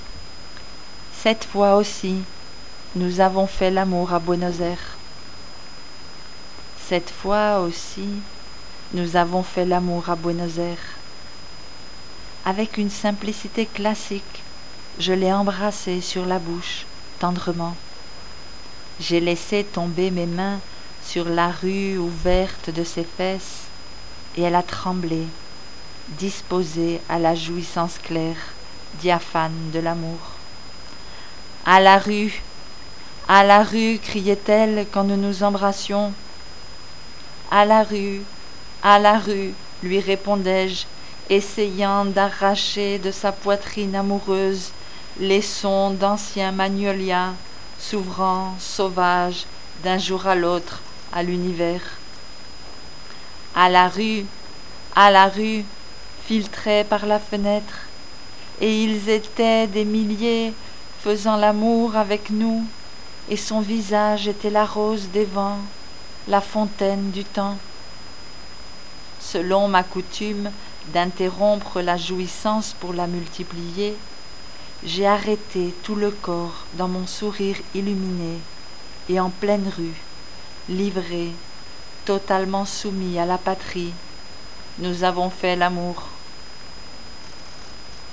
récite